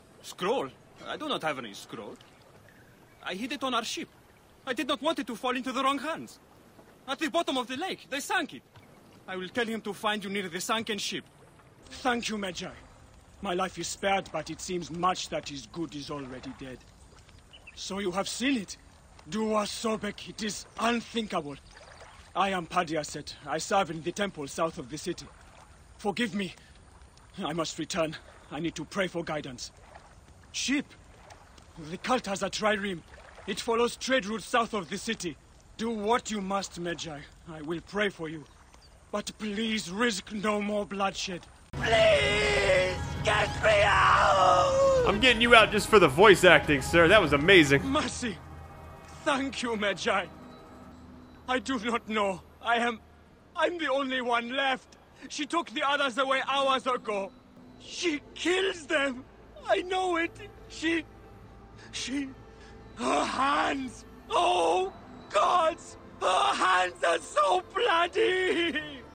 Jeux vidéo - ANG